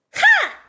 daisy_wah2.ogg